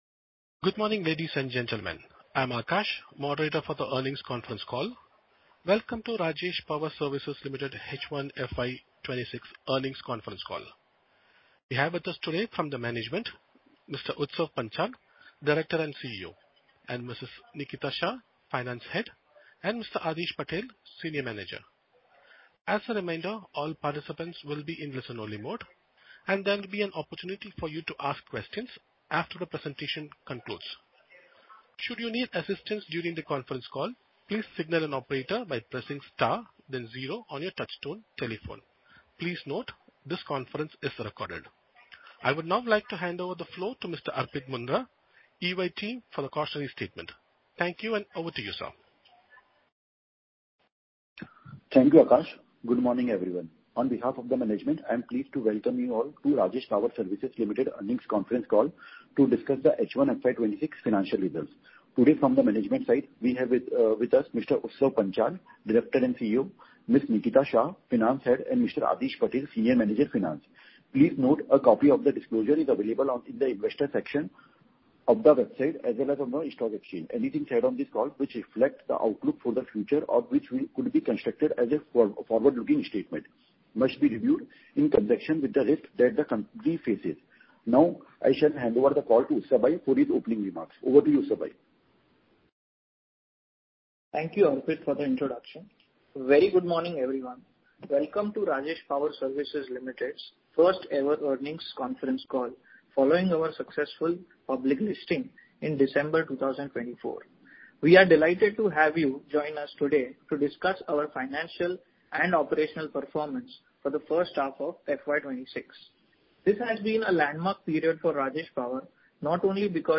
Audio Call Recording of Earnings Call Q2FY26 .mp3